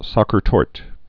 (säkər tôrt, zäər tôrtə)